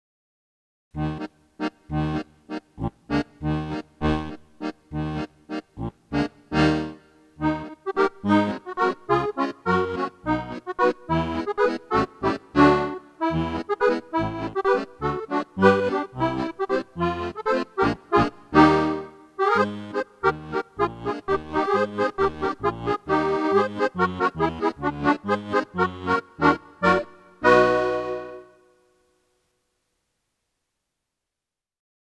Und ich habe eine schöne alternative Wendung gefunden, um auf die Subdominante zu modulieren: Alles Dur: 1, -7,-6.-5.4 und es hat starken Auflösungscharakter. -> siehe Klangbeispiel your_browser_is_not_able_to_play_this_audio (Also zum Beispiel man moduliert von C nach F, dann so: C-Dur, B-Dur, As-Dur, Ges-Dur, F ... und alles ist wieder schön.